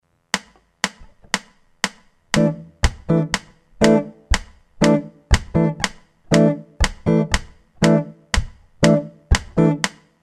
Es.4 ] che 2-3 [Es.5 ]; impropriamente si parla anche di Clave di Bossa, riferendosi a un pattern ritmico, sempre di cinque note, molto diffuso nella musica brasiliana [